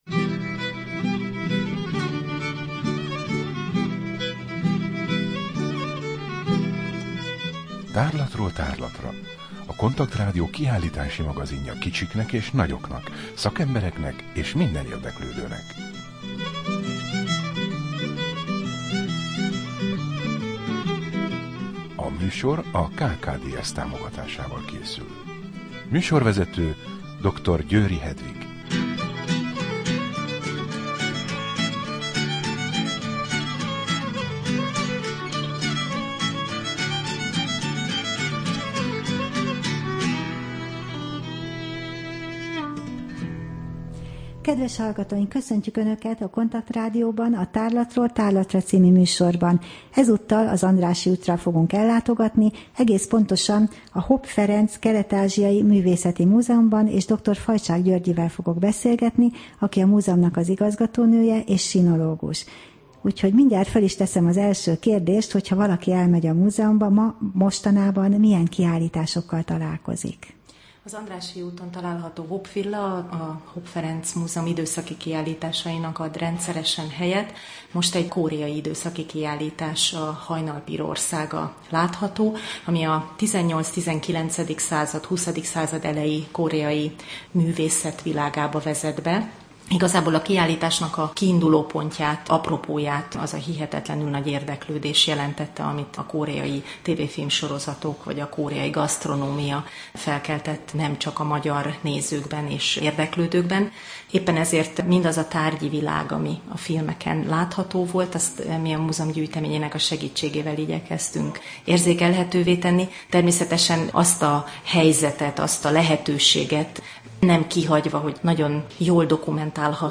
Rádió: Tárlatról tárlatra Adás dátuma: 2012, June 18 Tárlatról tárlatra / KONTAKT Rádió (87,6 MHz) 2012. június 18. A műsor felépítése: I. Kaleidoszkóp / kiállítási hírek II. Bemutatjuk / Hopp Ferenc Kelet-Ázsiai Művészeti Múzeum, Budapest